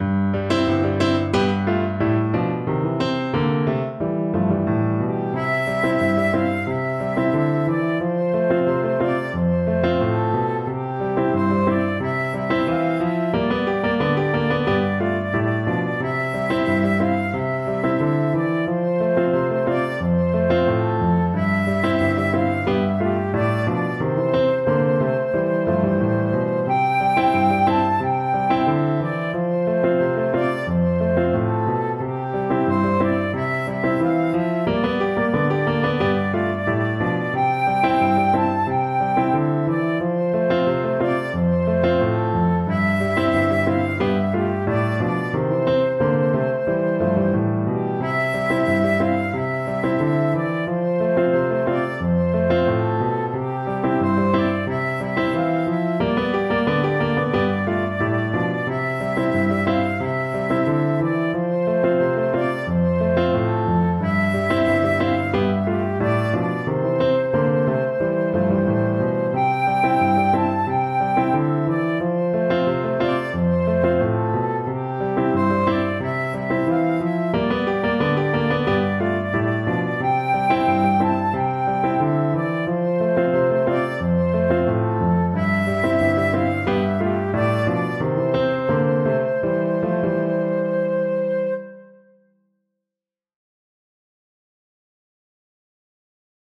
Flute
4/4 (View more 4/4 Music)
G5-A6
C major (Sounding Pitch) (View more C major Music for Flute )
Moderato =c.90
Traditional (View more Traditional Flute Music)